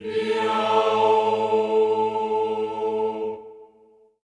HEE-AH  A2-L.wav